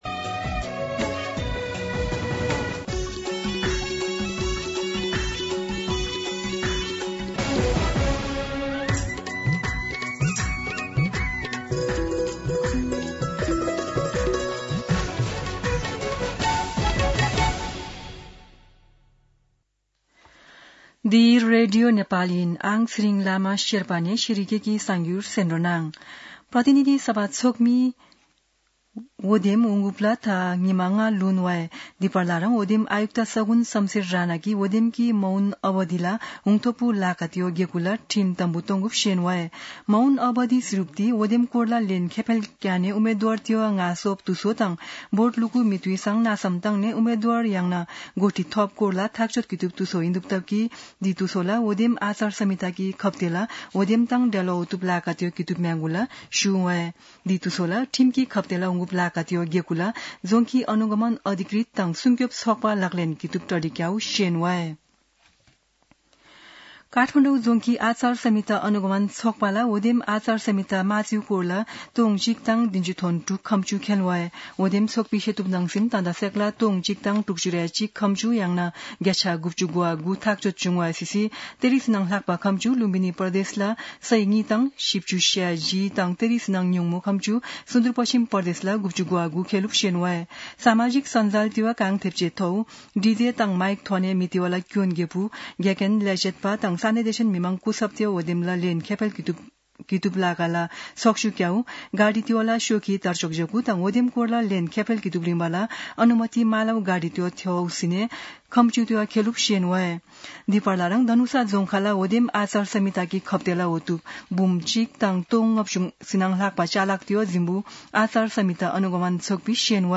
शेर्पा भाषाको समाचार : १६ फागुन , २०८२
Sherpa-News-16.mp3